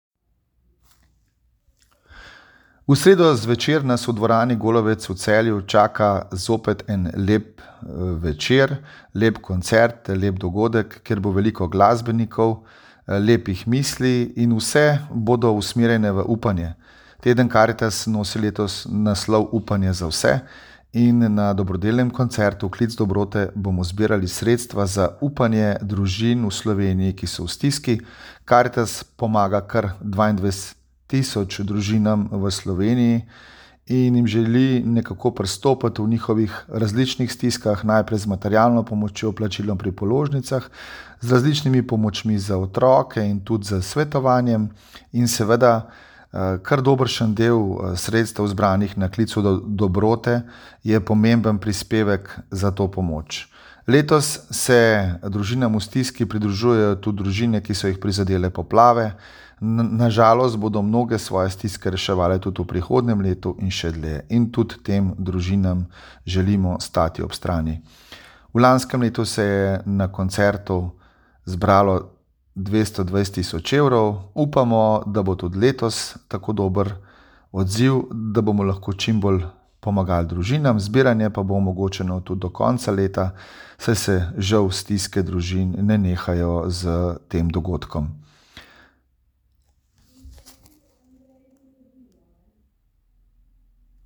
AVDIO POVABILO